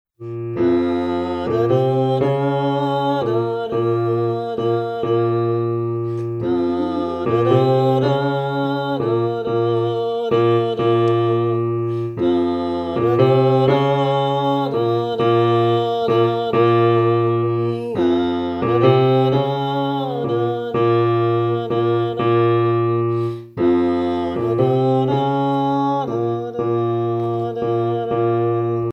Une petite interprétation au piano de la boucle capté en claire information avec du vocal de base.